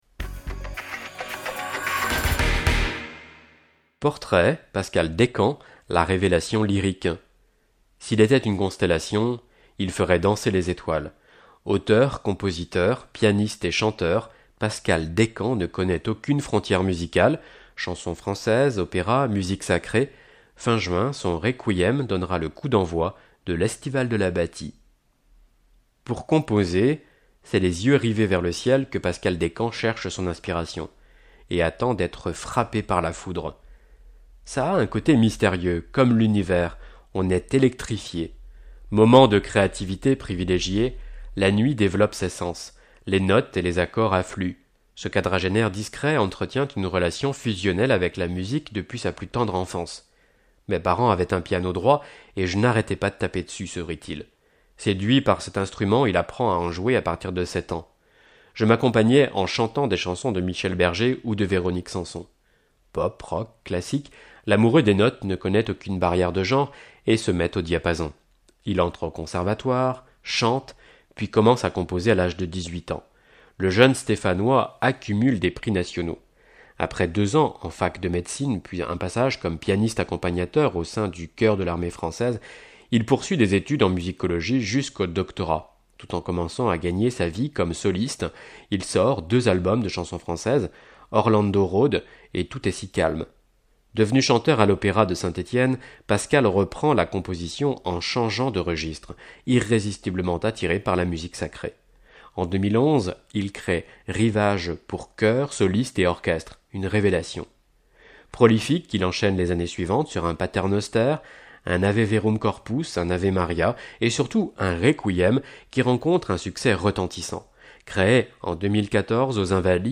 Loire Magazine n°123 version sonore